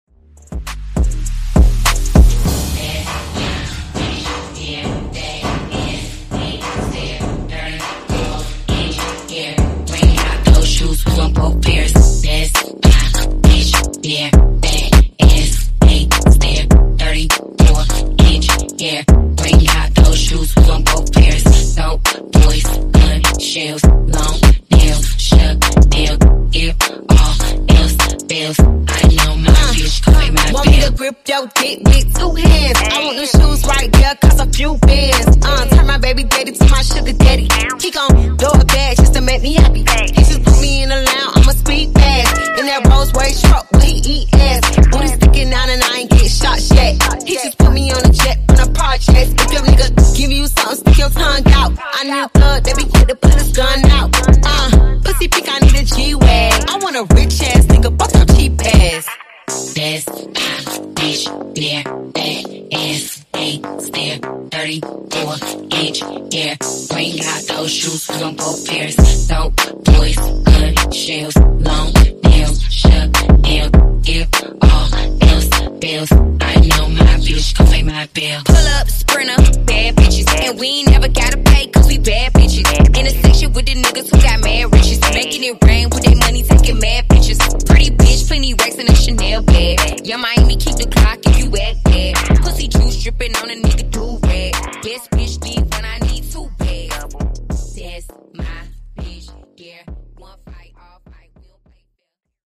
Genre: RE-DRUM Version: Dirty BPM: 98 Time